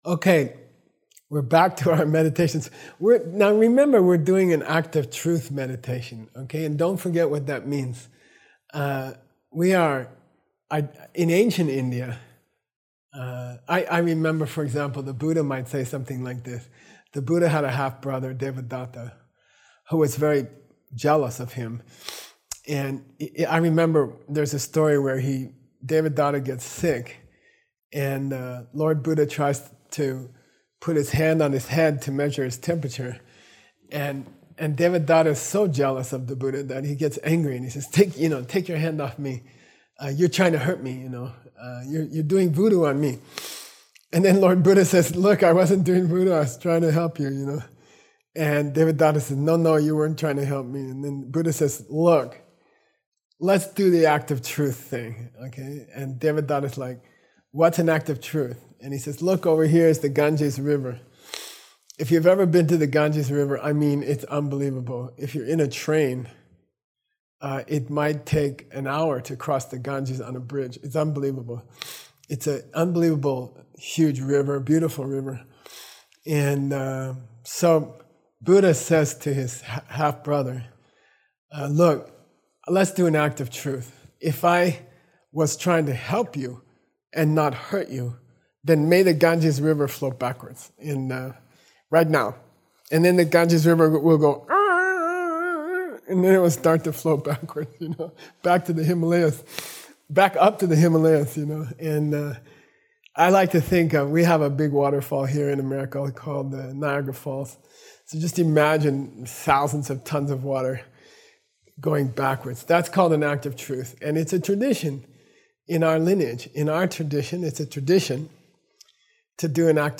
Meditation Audio